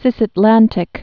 (sĭsət-lăntĭk)